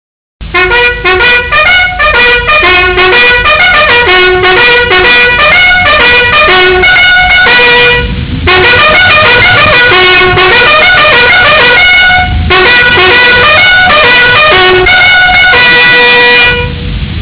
Segnali di tromba
sveglia.au